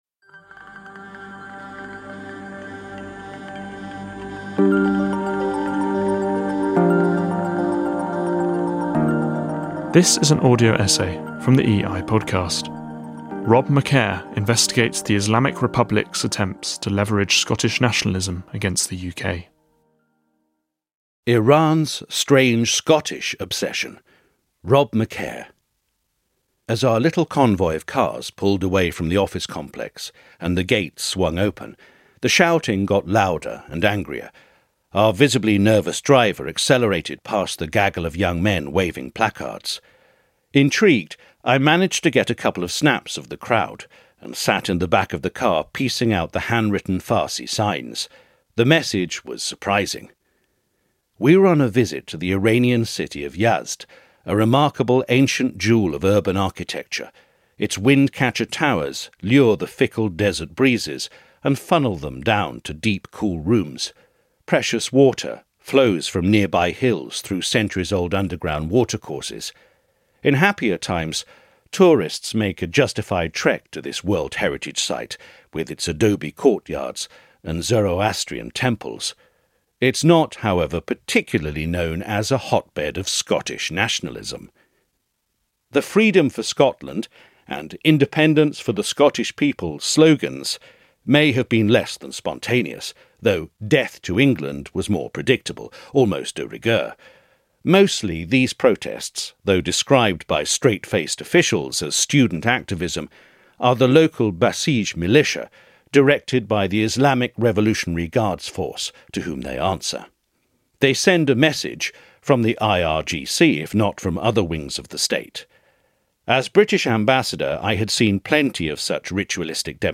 From placard-waving crowds in Yazd to troll farms on social media, the Islamic Republic has long tried to wield Scottish nationalism as a weapon against the UK. This audio essay is read